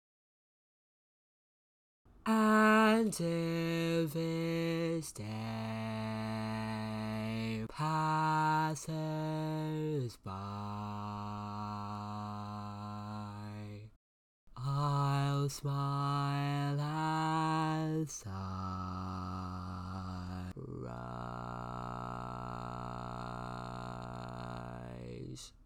Key written in: B Major
Each recording below is single part only.